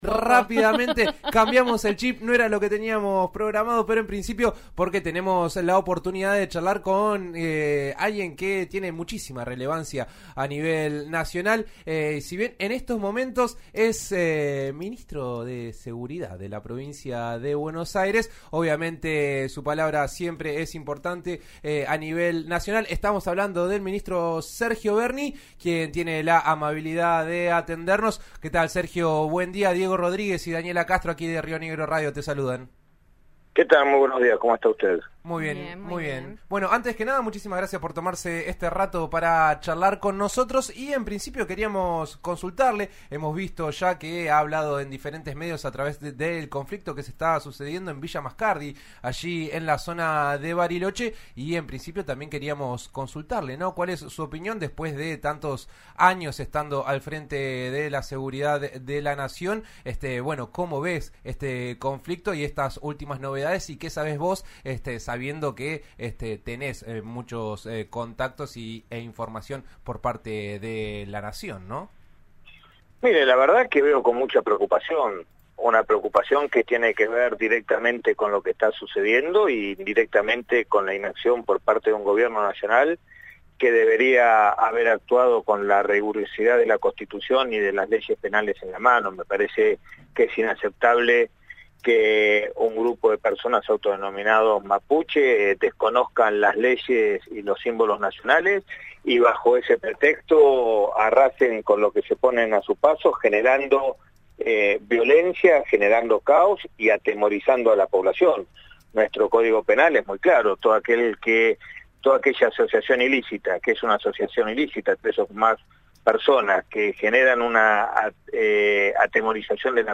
En eso estamos de RN Radio dialogó con el Ministro de Seguridad de la provincia de Buenos Aires, Sergio Berni, sobre el conflicto en Villa Mascardi. El funcionario apuntó contra el gobierno nacional y la Justicia.